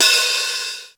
HiHatOp.wav